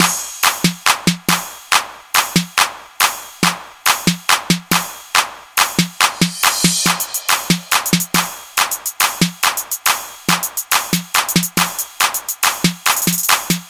沉重的锤子
描述：吉他上典型的'锤子'。在许多Hendrix的曲子中使用，这个声音实际上是一个'拉开'，从D（开弦）开始，反复拉动E弦。用带有无噪音拾音器的Fender Stratocaster录音，通过DigiTech 2101专业艺术家处理器播放，
Tag: 失真 吉他